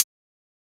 Hihat 3.wav